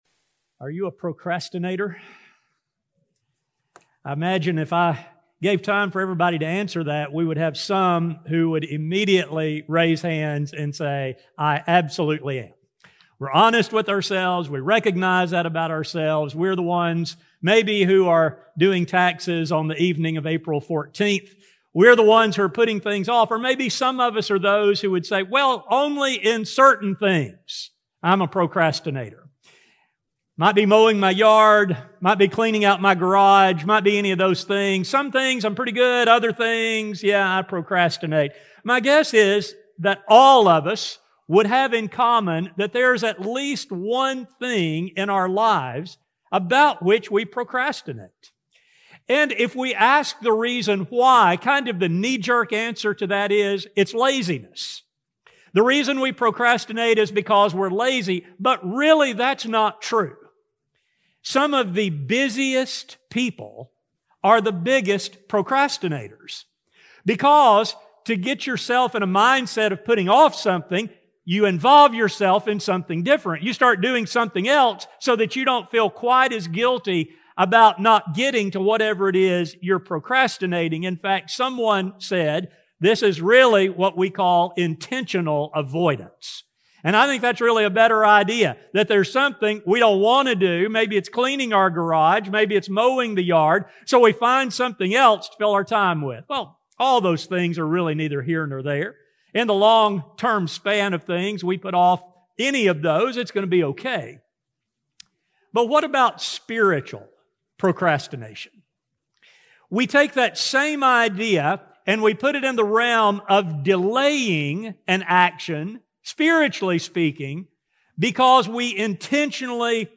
Why would one put off a relationship with God until tomorrow when His blessings can be enjoyed today? A sermon